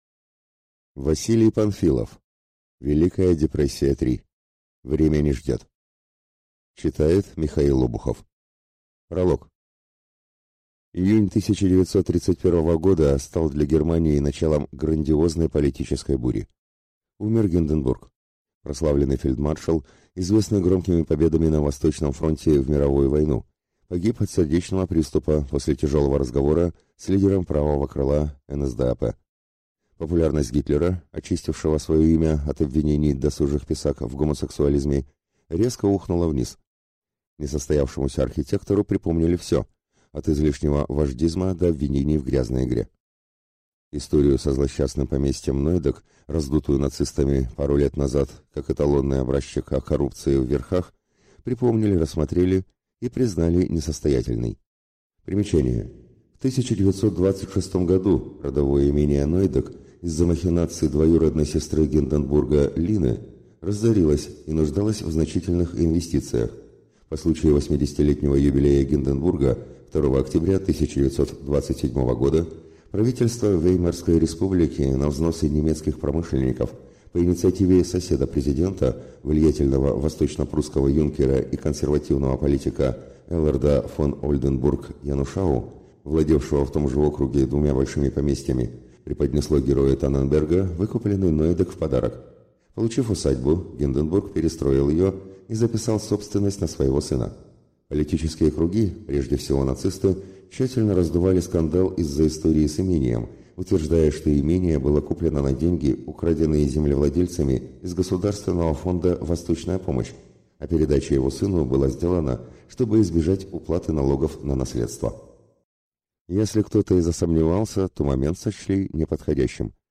Aудиокнига Время не ждет!